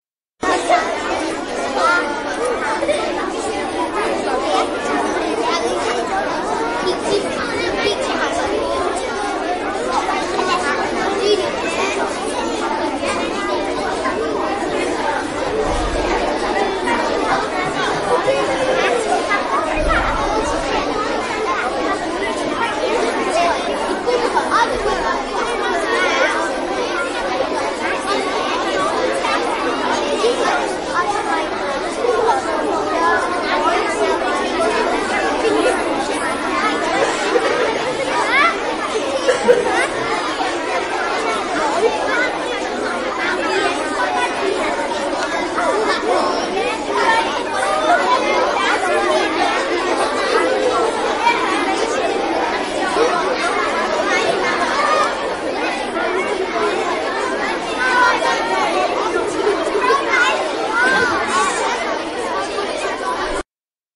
دانلود صدای بچه ها در کلاس مدرسه از ساعد نیوز با لینک مستقیم و کیفیت بالا
جلوه های صوتی